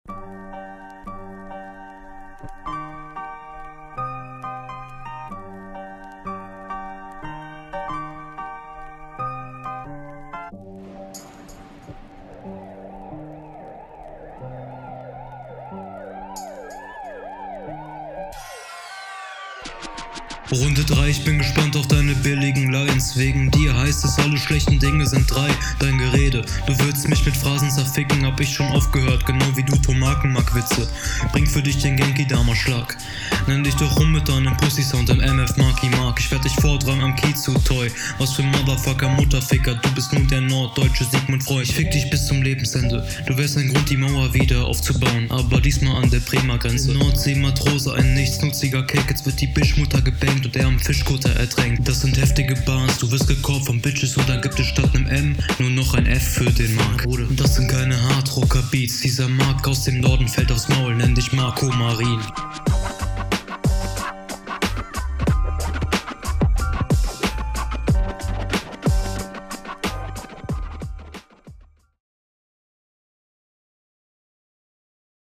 beste runde des battles ganz solide gerappt paar nette lines leider nicht so viel bezug …
hr 3 imo die beste bisher im battle. beat dope, und flow mag ich. sound …